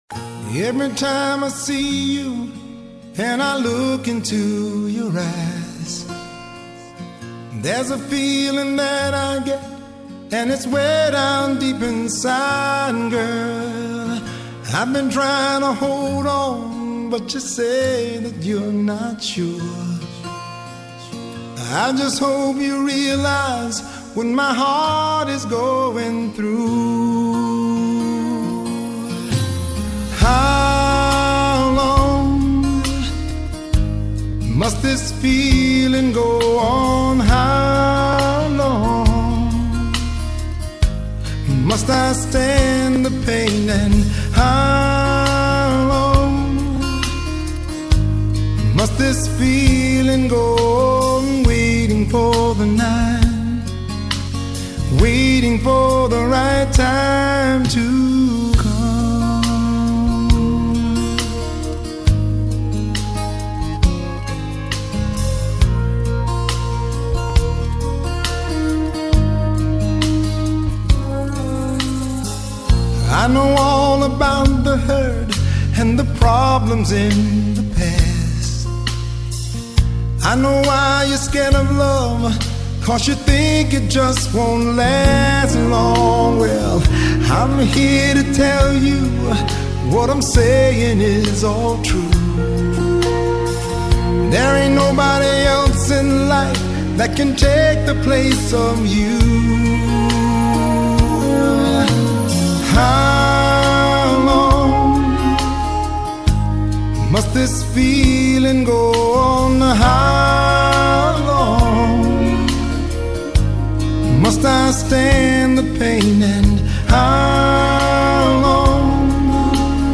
neizvesten___horoshaya_medlennaya_kompaziciya.mp3